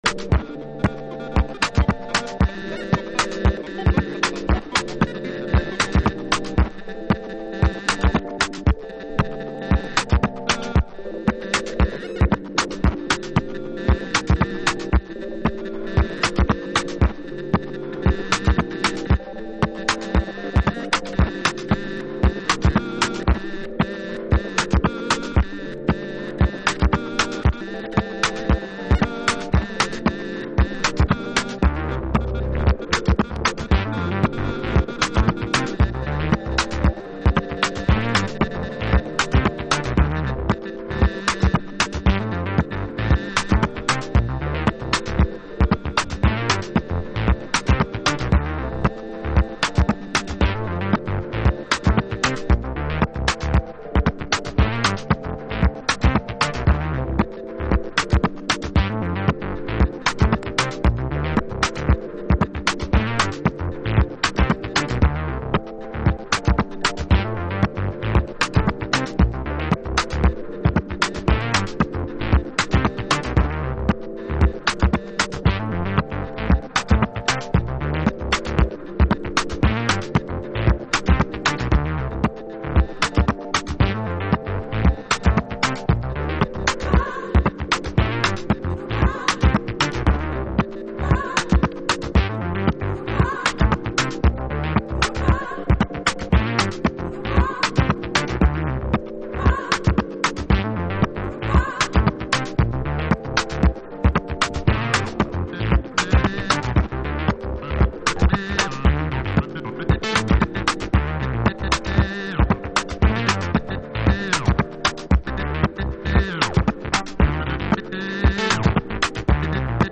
Jazz / Crossover
名曲のリメイクを含めながら70年代ブラックジャズの精神性も引き継いだサウンド。